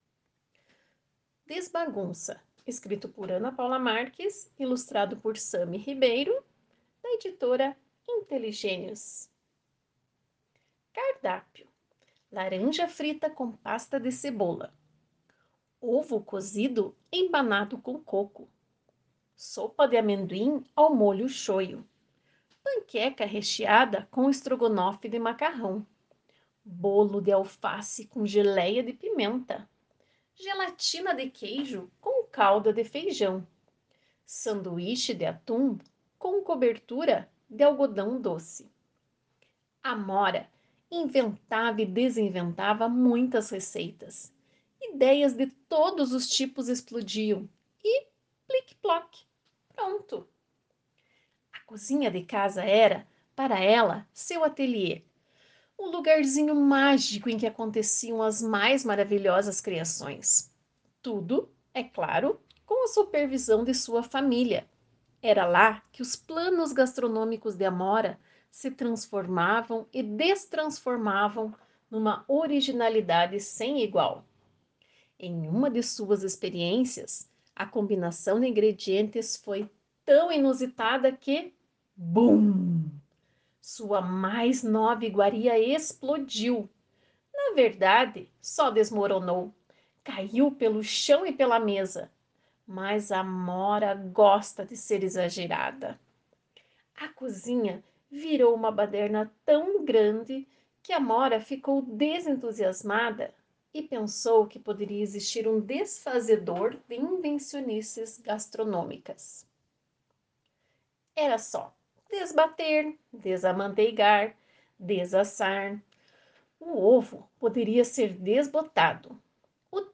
Leitura Guiada